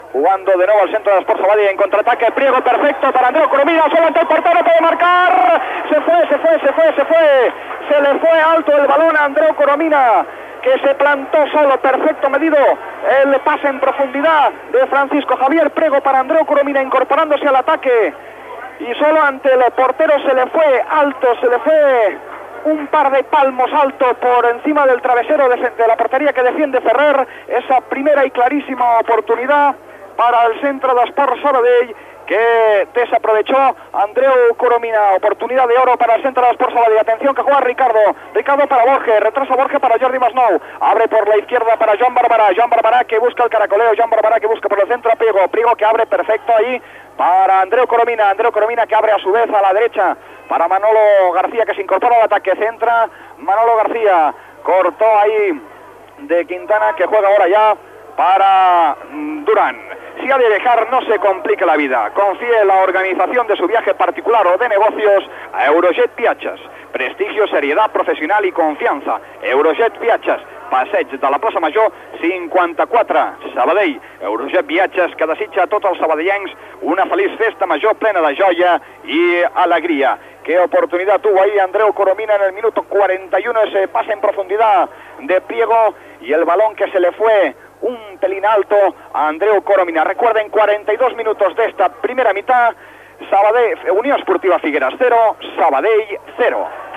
Fragment de la transmissió del partit de futbol de la segona divsió masculina entre la Unió Esportiva Figueres i el Club Esportiu Sabadell
Esportiu